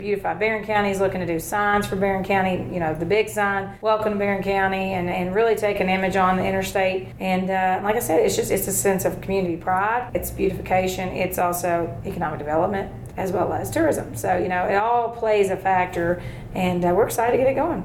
Magistrate District 3, Tim Durham, expressed why the county government should consider taking a chance on this new approach.